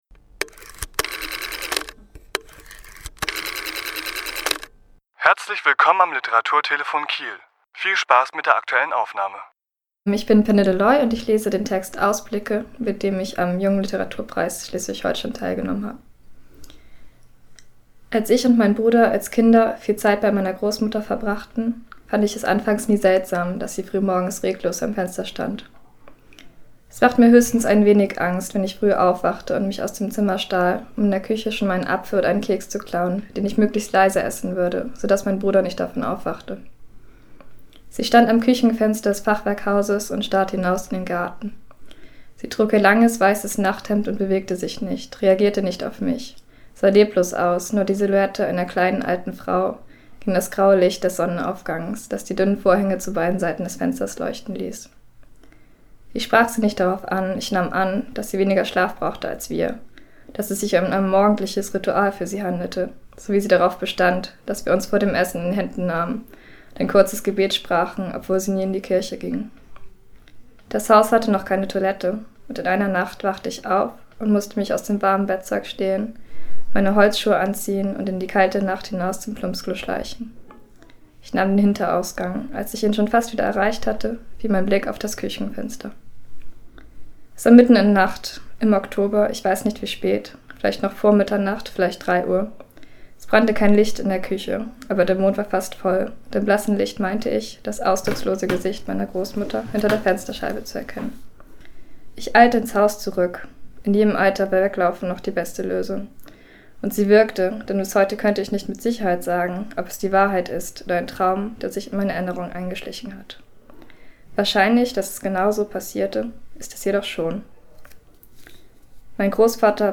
Autor*innen lesen aus ihren Werken
Die Aufnahme entstand im Rahmen der Lesung zur Preisverleihung im Literaturhaus S.-H. am 25.4.2017.